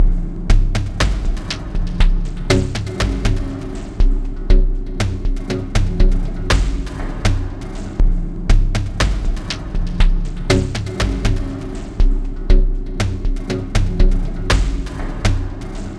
Downtempo 05.wav